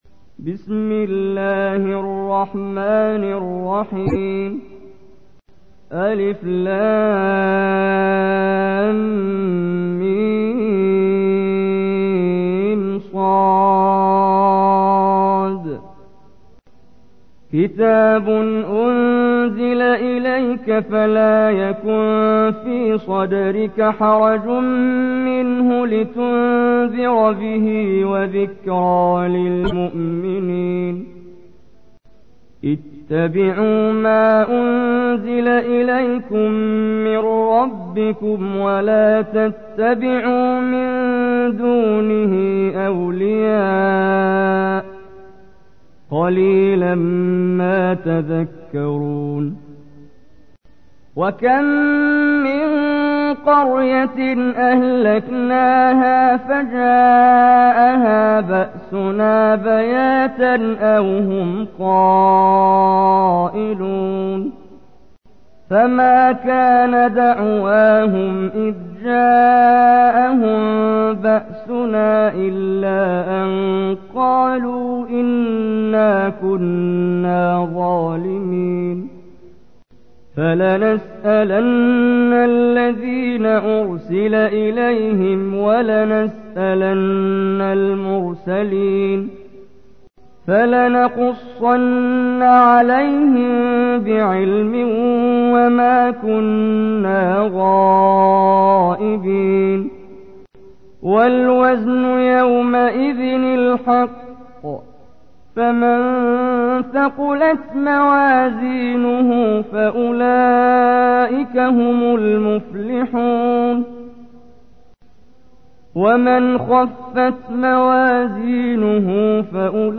تحميل : 7. سورة الأعراف / القارئ محمد جبريل / القرآن الكريم / موقع يا حسين